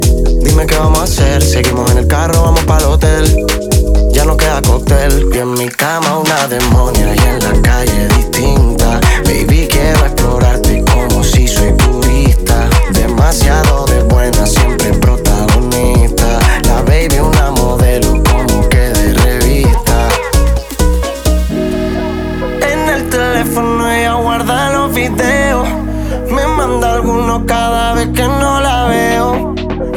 Жанр: Электроника / R&b / Соул